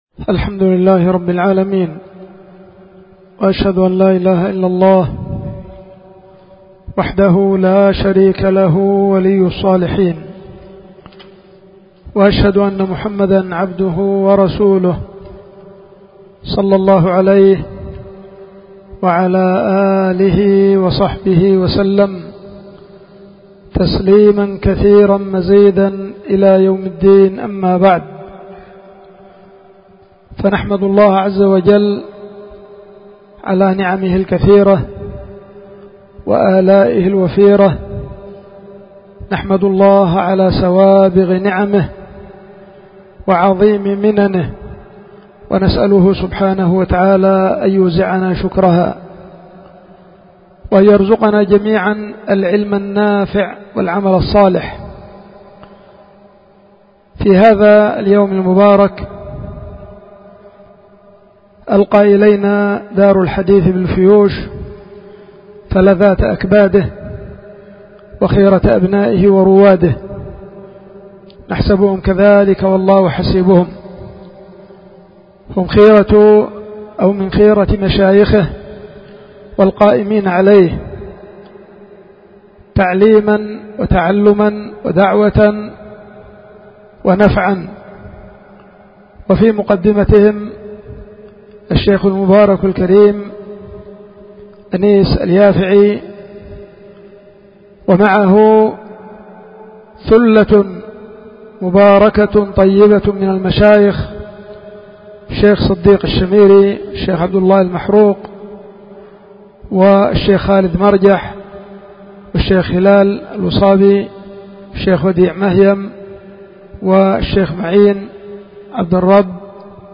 كلمة ترحيبية بمشايخ مركز الفيوش
وذلك يوم الخميس 14جمادى الأولى لعام 1444هجرية بعد صلاة العصر
في دار الحديث السلفية في مدينة دار الــسـلام العلمية في يختل المخا